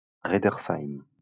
Raedersheim (French pronunciation: [ʁɛdəʁsaim]